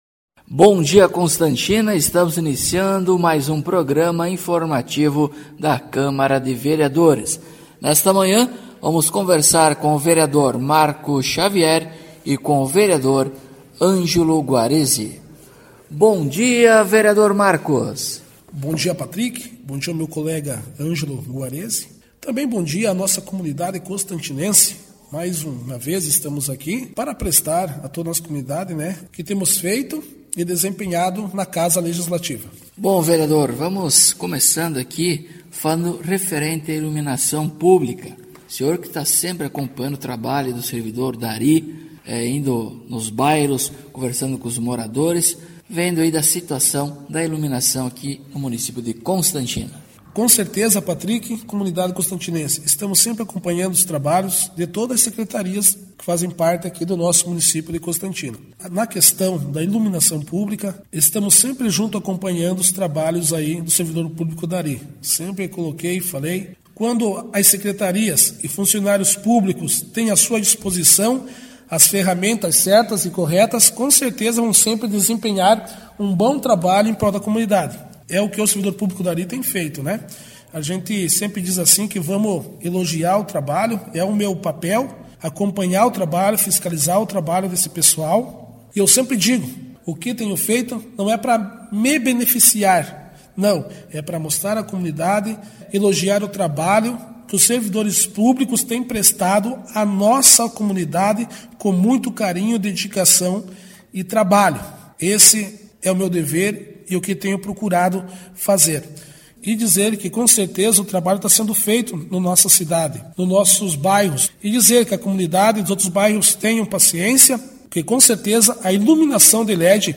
Acompanhe o programa informativo da câmara de vereadores de Constantina com o Vereador Marco Xavier e o Vereador Ângelo Guarezi.